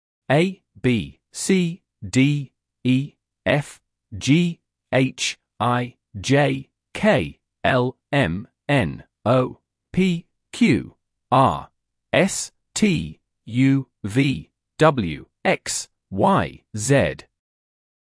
Lettre Nom en anglais Exemple de mot Prononciation approximative en français
A ei apple éï
H eitʃ hat èïtch
Z zed (UK) / zi (US) zebra zèd / zi
• H se prononce “eitʃ” et n’est jamais muet.